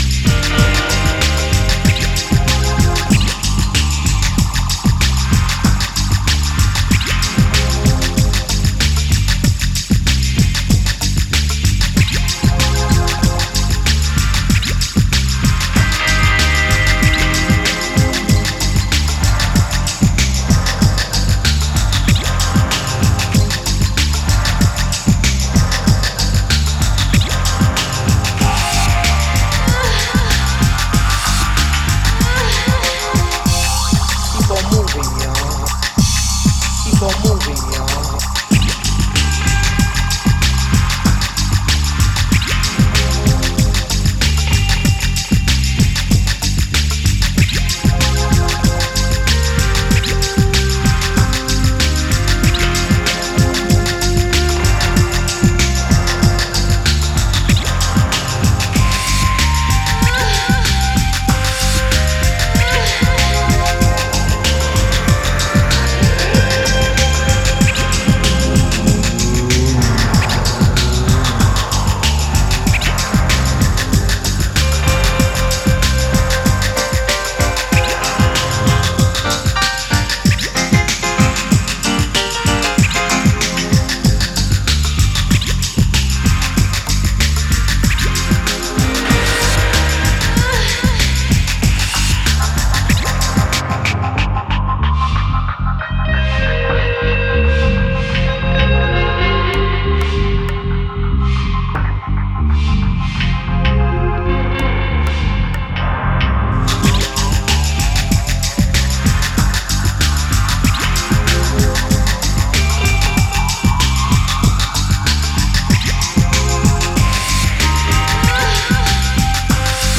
ソリッドでダビーなブレイクビーツ〜ダウンテンポ路線に仕上がっていて全曲それぞれナイス。